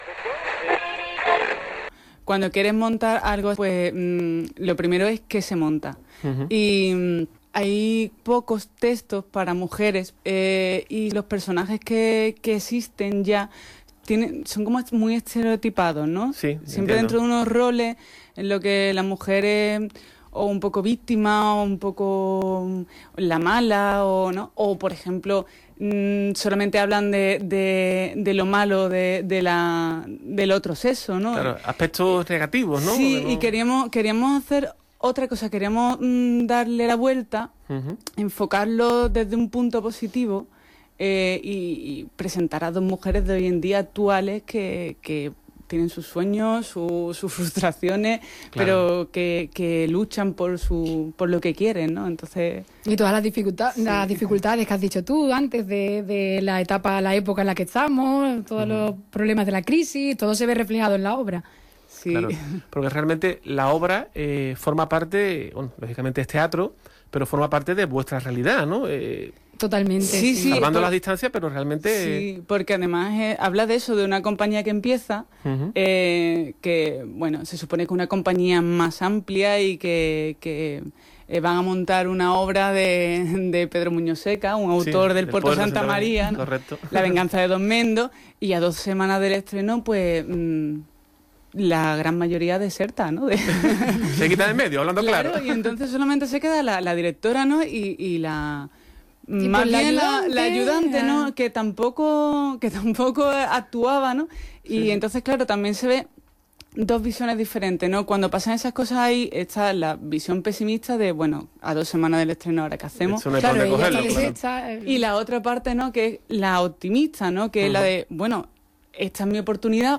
Presentación de la obra "En tu casa o en la mía" en el programa cultural "Arte con H" de Onda Jerez Radio.